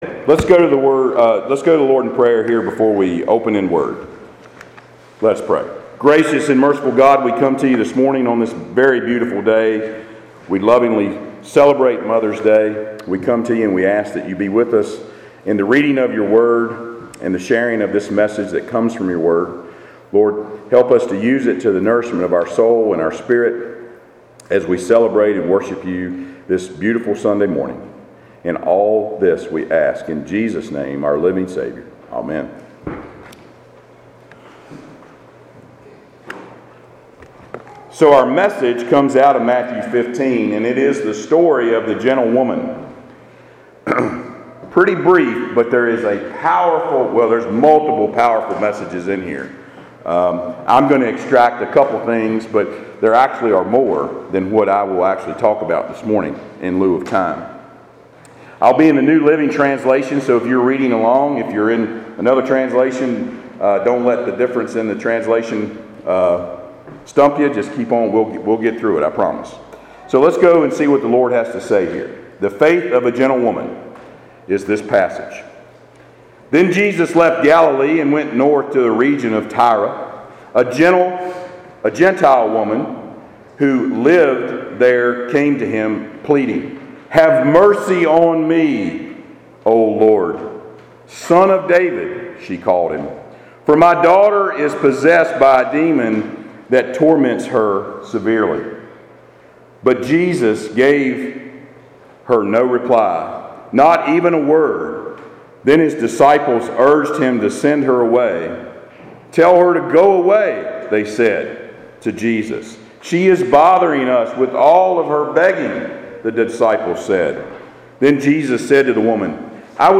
Passage: Matthew 15:21-28 Service Type: Sunday Worship